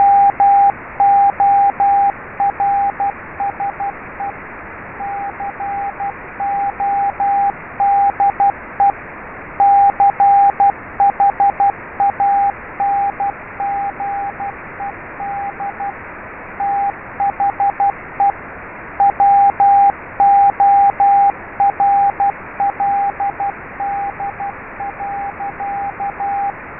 And then you can also add interferences, noise and fading.
As a result, the WAV files are reasonably small and the pitch of the noise is limited to a SSB bandwidth.
Morse and noise